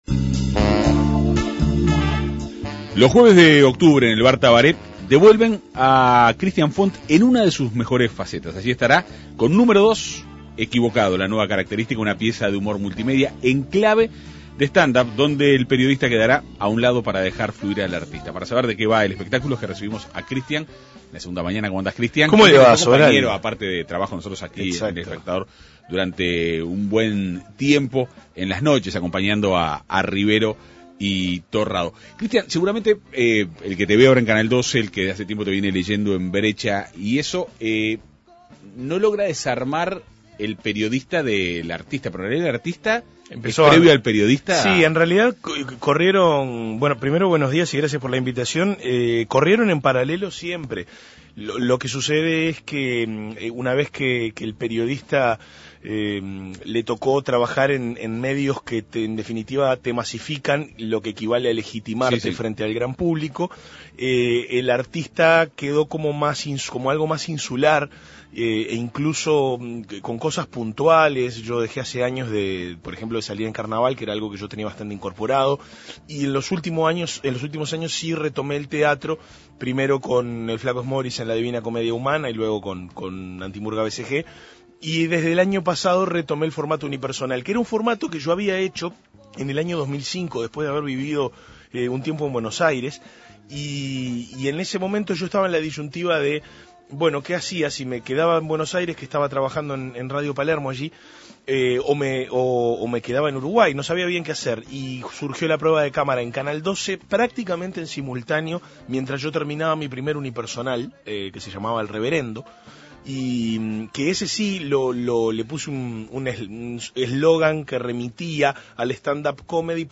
La Segunda Mañana de En Perspectiva dialogó con él.